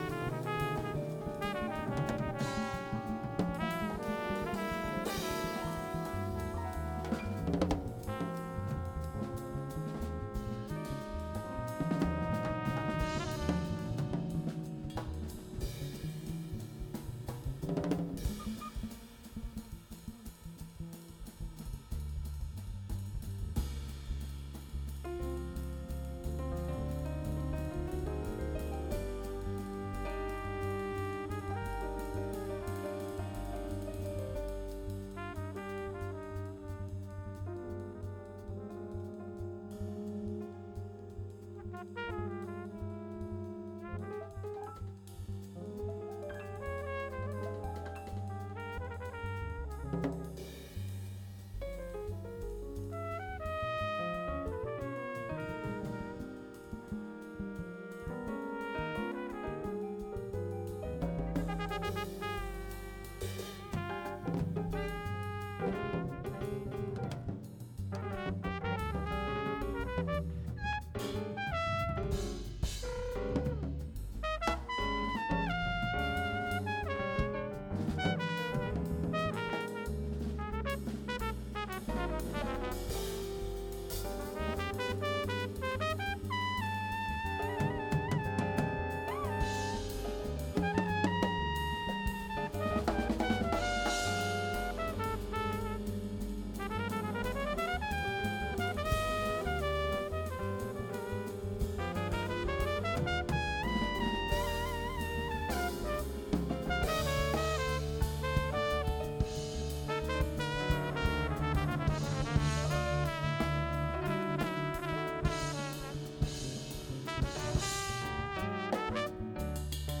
chats new creative horizons